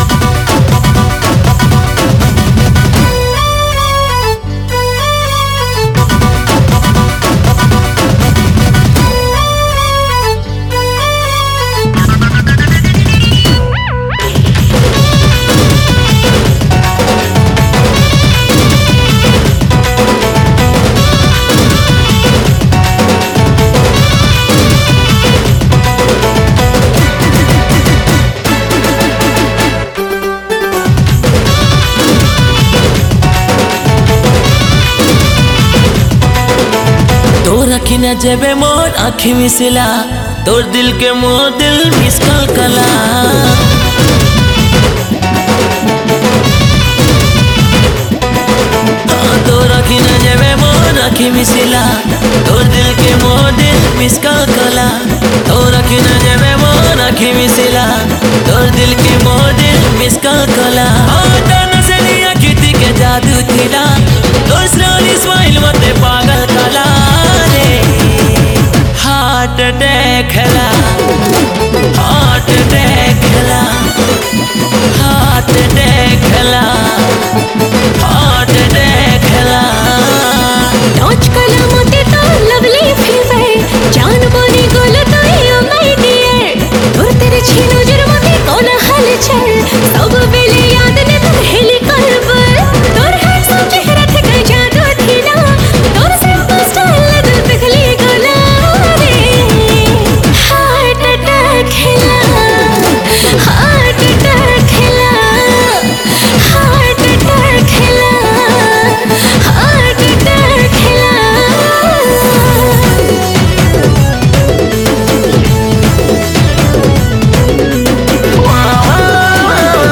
Sambalpuri